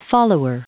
Prononciation du mot follower en anglais (fichier audio)
Prononciation du mot : follower